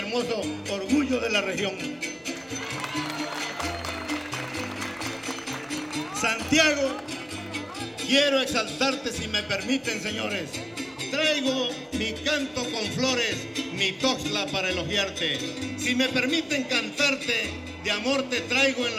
Concurso Estatal de Fandango